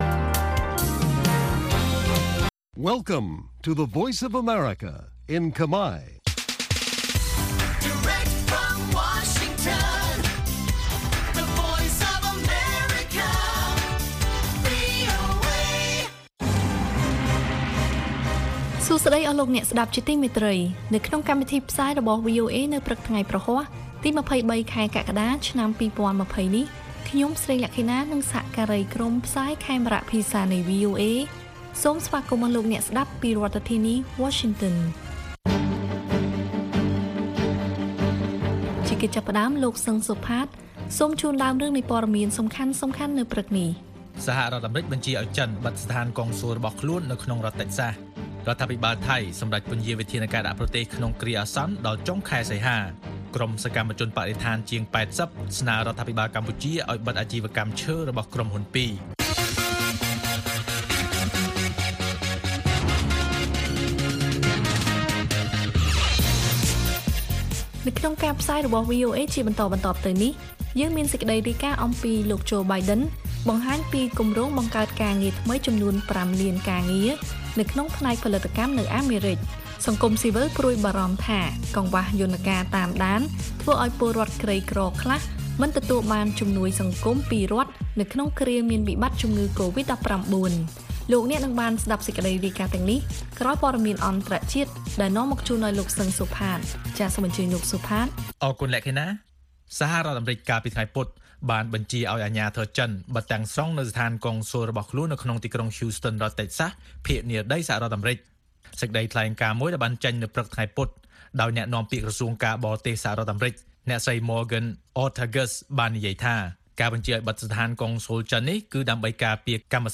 ព័ត៌មានពេលព្រឹក